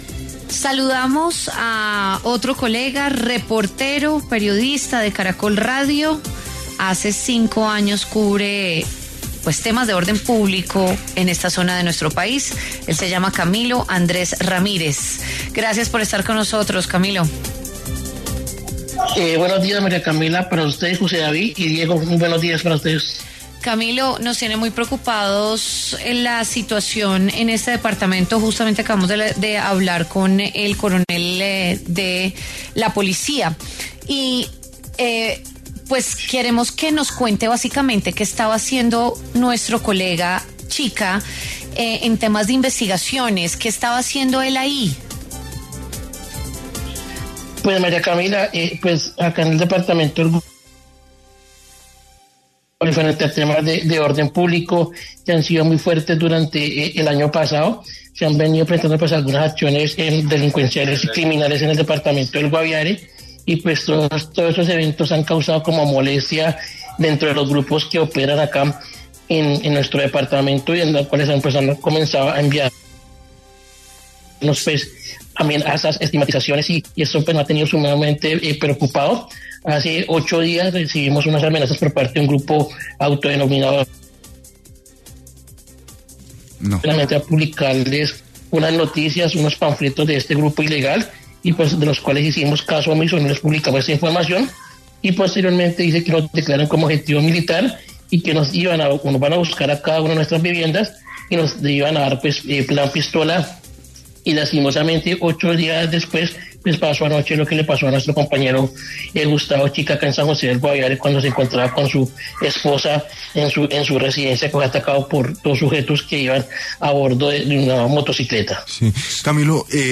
En diálogo con W Fin de Semana